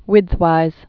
(wĭdthwīz, wĭth-, wĭtth-)